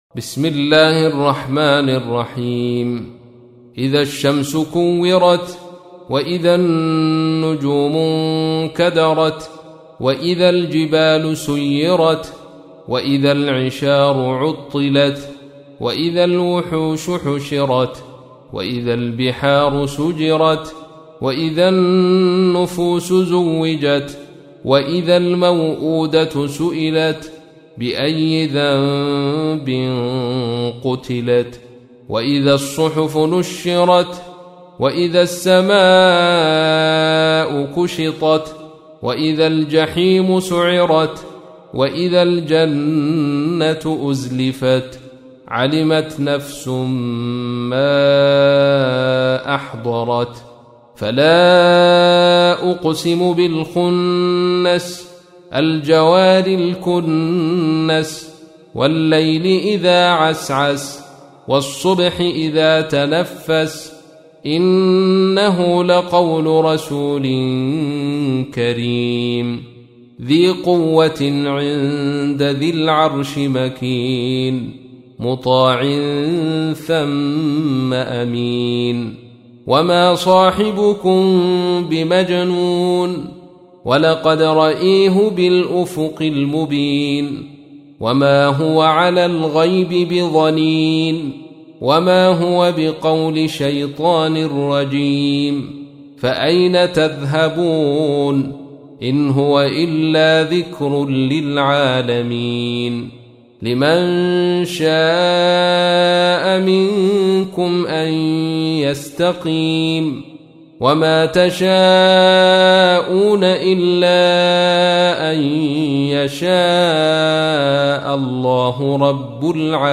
تحميل : 81. سورة التكوير / القارئ عبد الرشيد صوفي / القرآن الكريم / موقع يا حسين